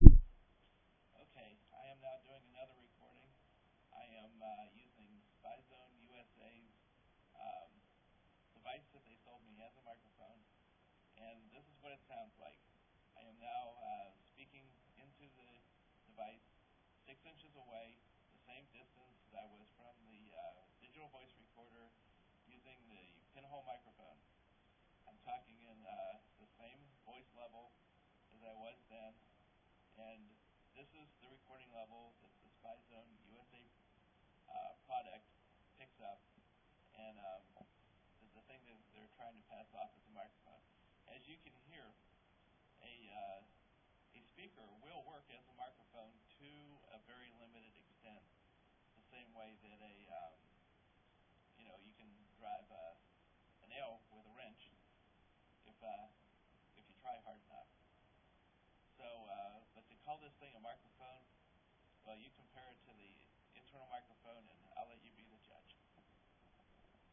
The levels were about 30 decibels below mic levels and the sound was like being in a barrel - which is about what you get when you try to turn a speaker into a mic.
I recorder 2 MP3 files using the same digital voice recorder.
You may have to turn the volume up on your speakers because there really is some audio there. But as you can see, there's a big difference between a microphone - a real microphone - and trying to use what is essentially a speaker as a microphone.